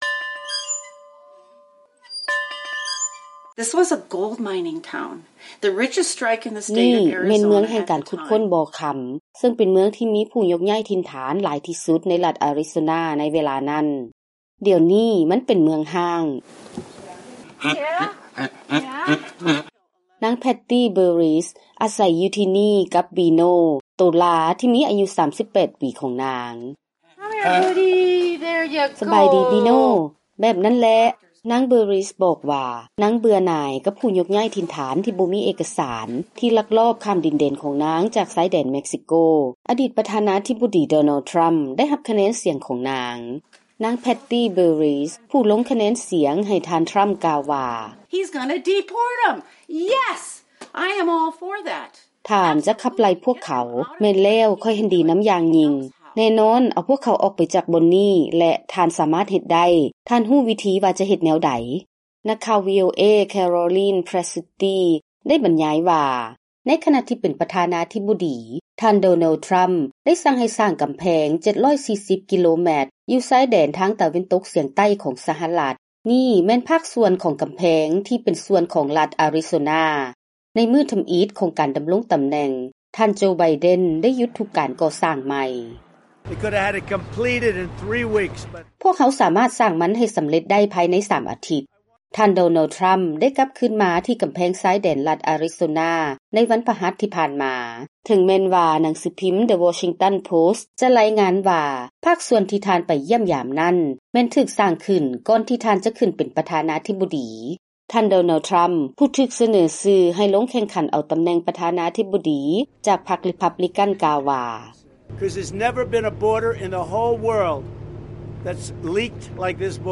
Donald Trump, Republican Presidential Nominee:
Kamala Harris, Democratic Presidential Nominee: